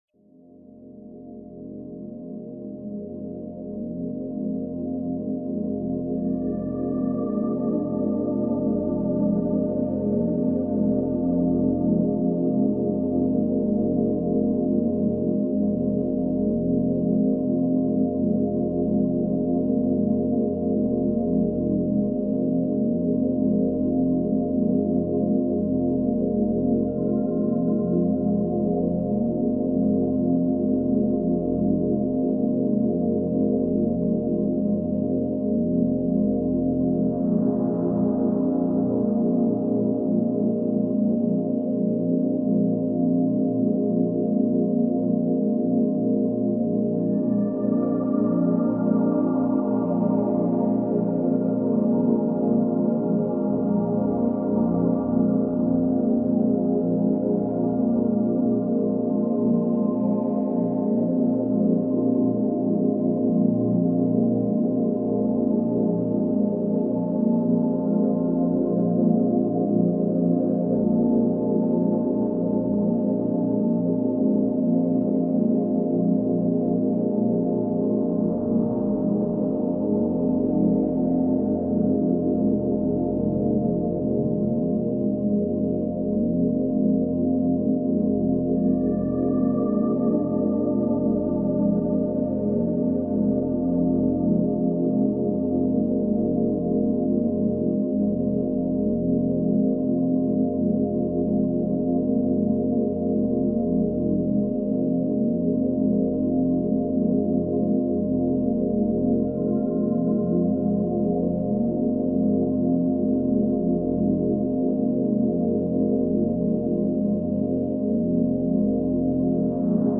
Sacral Chakra Meditation – 606 Hz for Emotional Balance